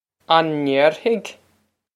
Pronunciation for how to say
On nayr-hig?
This is an approximate phonetic pronunciation of the phrase.